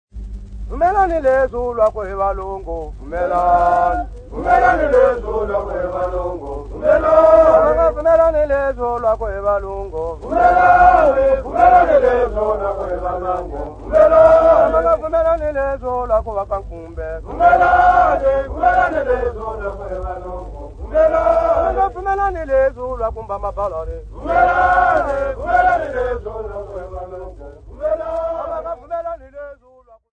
Tswa men
Folk Music
Field recordings
Africa Mozambique city not specified f-mz
Indigenous music